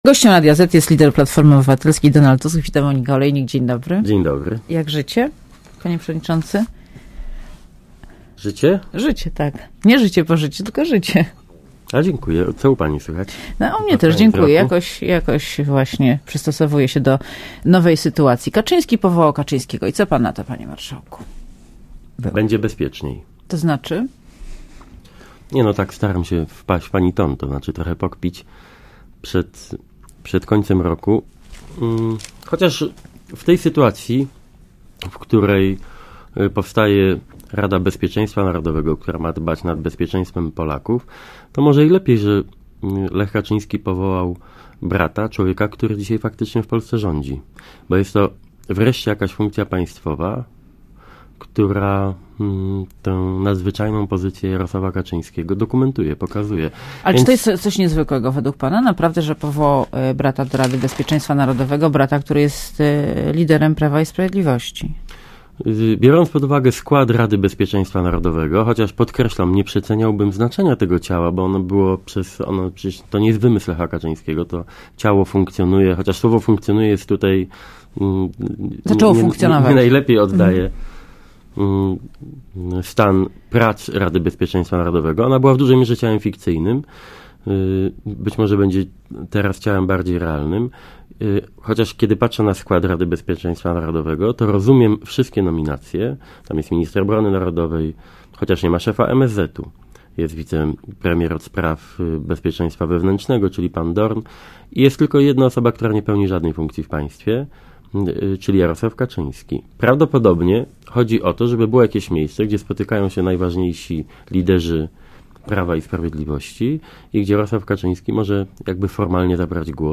Posłuchaj wywiadu Gościem Radia ZET jest lider Platformy Obywatelskiej Donald Tusk .